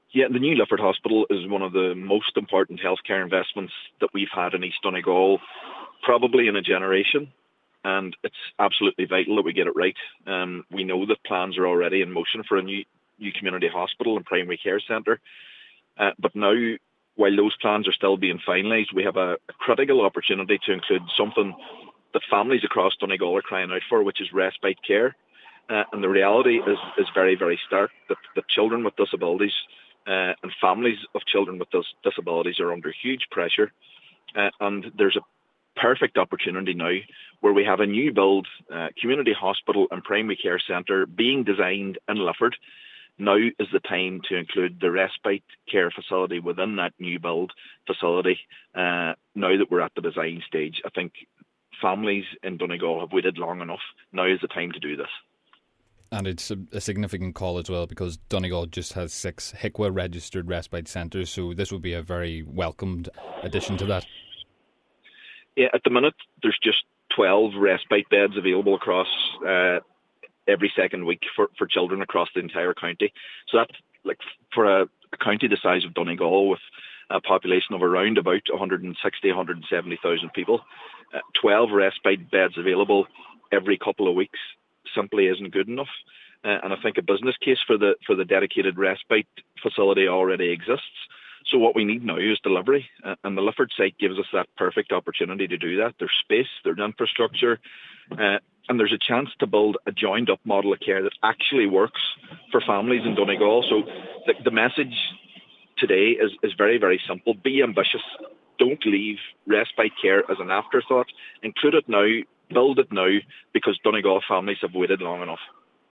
He says that it is a perfect opportunity to include respite care in this new facility, which will make a difference: